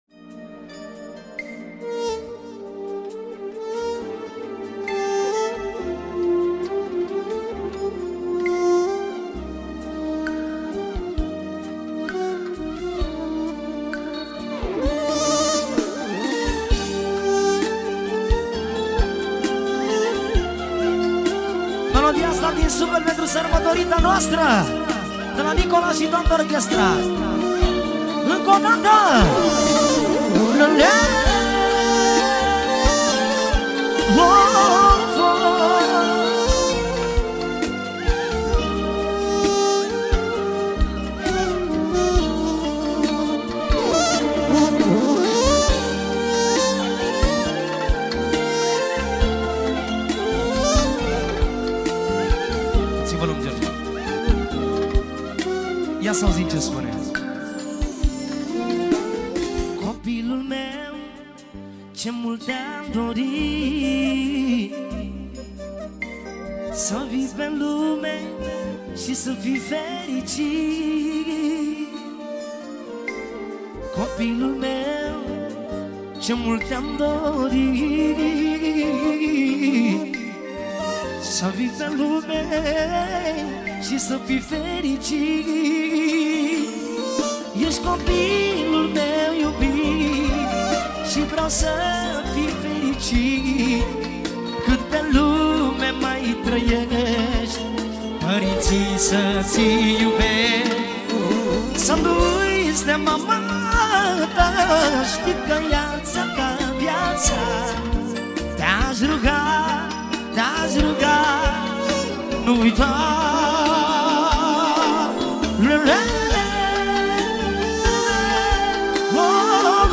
(live Track)
Data: 22.10.2024  Manele New-Live Hits: 0